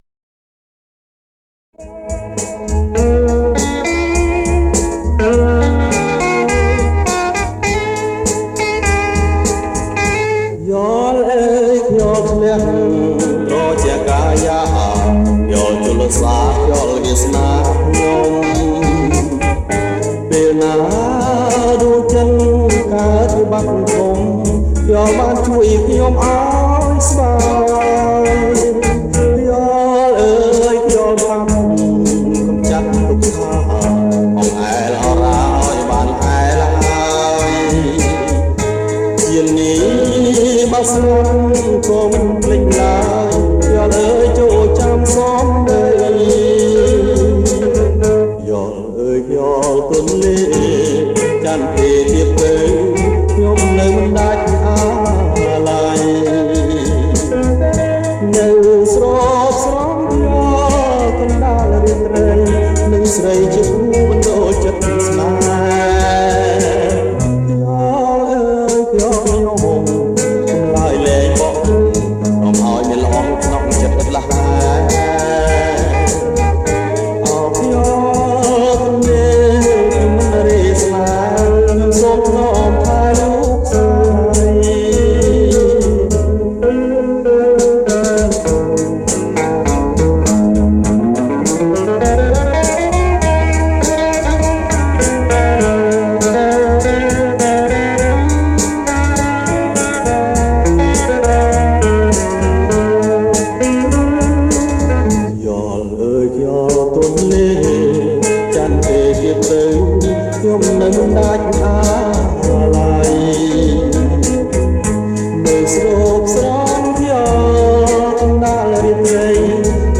ប្រគំជាចង្វាក់ Bolero